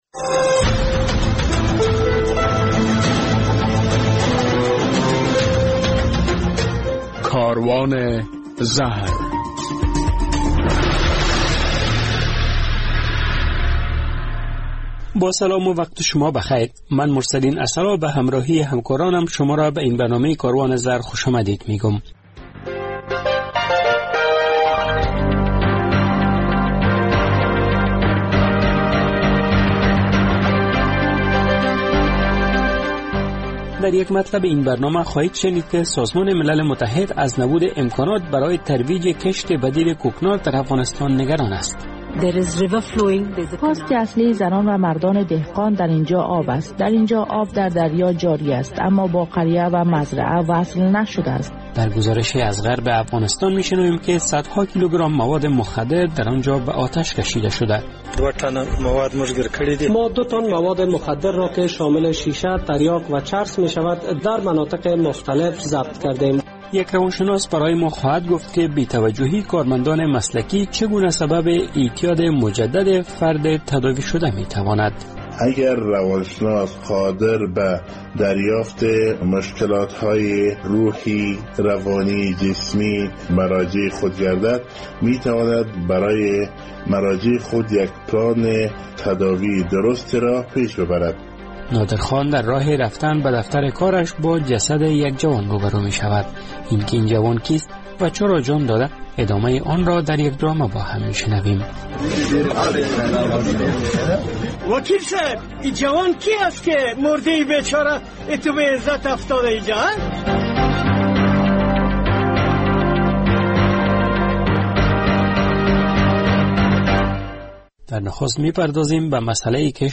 در این برنامه کاروان زهر می‌شنوید که سازمان ملل متحد می‌گوید، نبود سیستم منظم آبرسانی برای مزارع سبب شده که کشت‌های بدیل کوکنار حاصل بهتر ندهد. در یک گزارش از غرب افغانستان می‌شنوید که صدها کیلوگرام مواد مخدر در هرات و نیمروز به آتش کشیده شده.